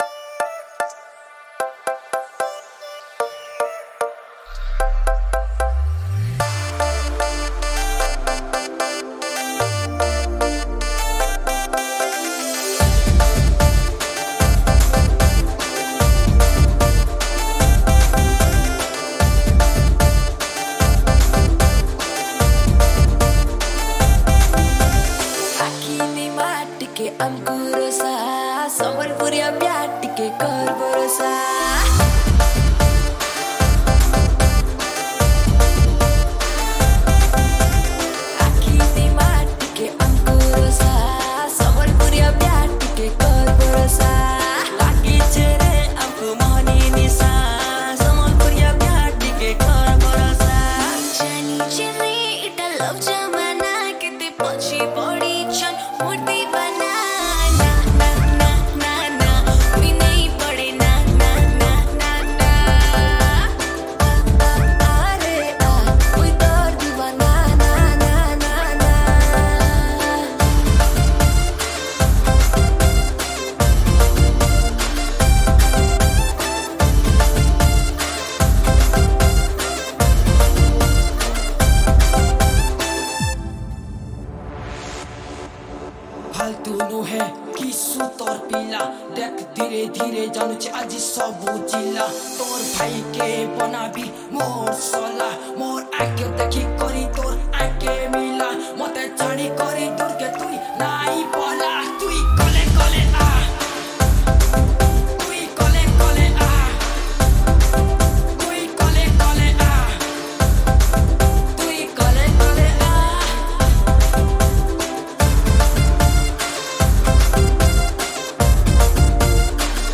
Category: New Sambalpuri